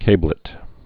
(kāblĭt)